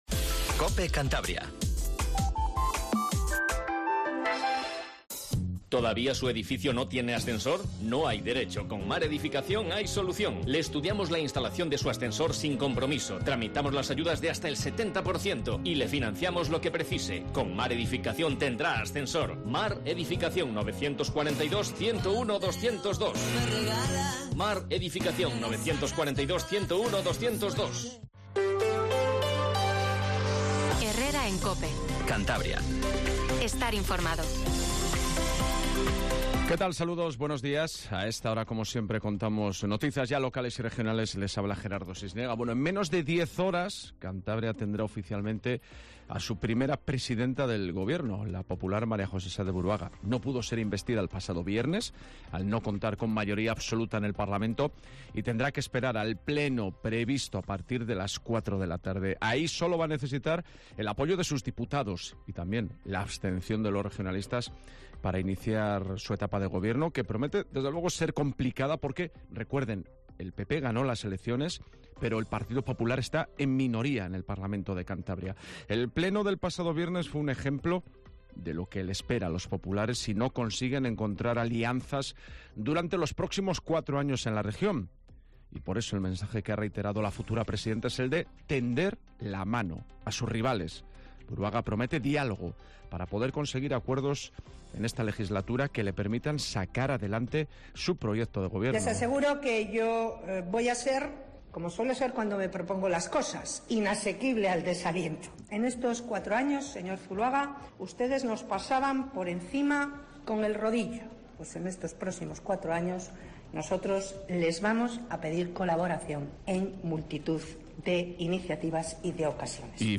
Informativo Matinal Cope 07:20